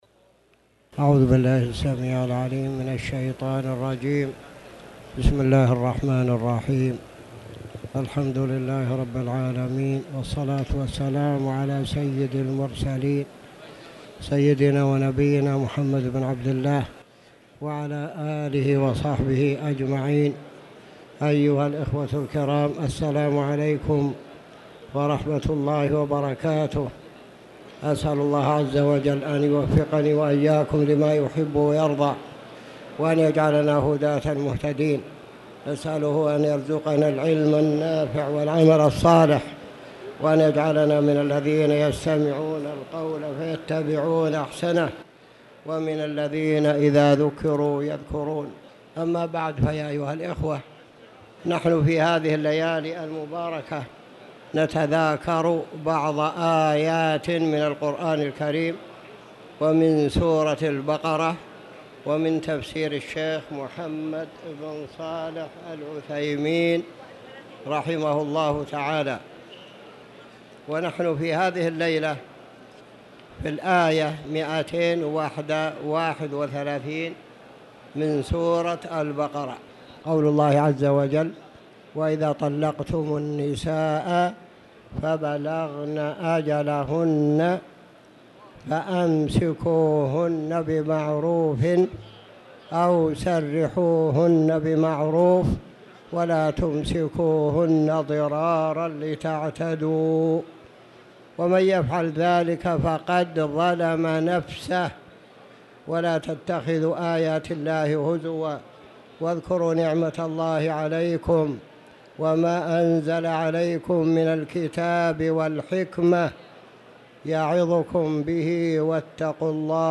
تاريخ النشر ٢٣ رمضان ١٤٣٧ هـ المكان: المسجد الحرام الشيخ